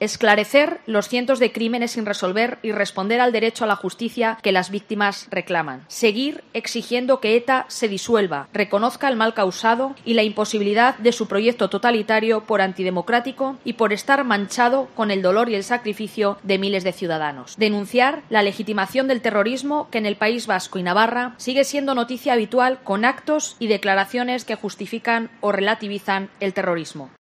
Ha incluido la lectura de un manifiesto, un minuto de silencio y una ofrenda floral ante el Monumento a las Víctimas del Terrorismo, obra de Agustín Ibarrola.
La alcaldesa de Logroño, Cuca Gamarra, ha sido la encargada de dar lectura al manifiesto, que ha comenzado recordando la conmoción social que produjo en toda España el secuestro y asesinato de Miguel Ángel Blanco.